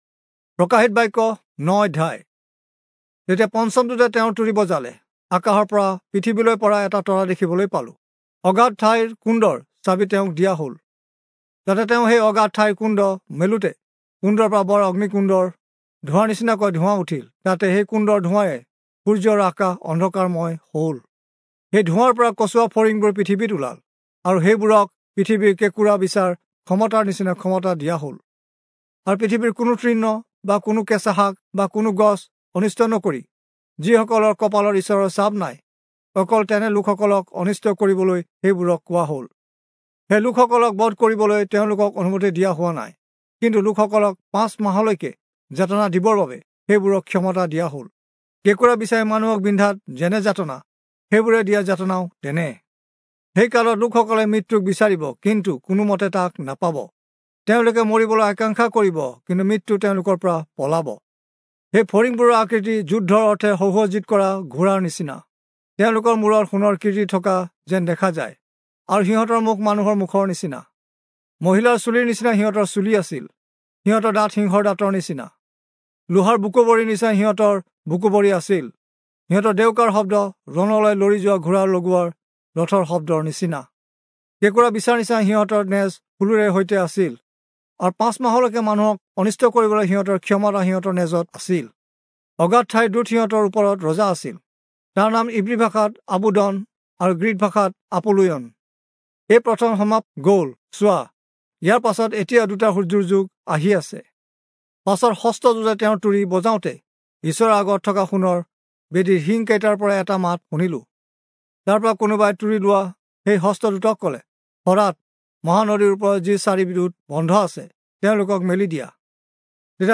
Assamese Audio Bible - Revelation 11 in Ocvml bible version